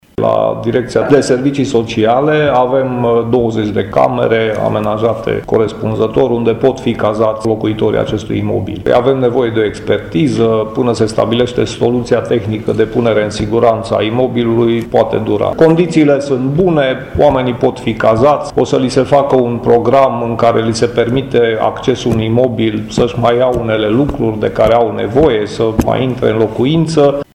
Deja, două familii, din cele 16 locatare ale blocului, s-au mutat în spațiile puse la dispoziție de municipalitate, după cum a precizat viceprimarul Laszlo Barabaș: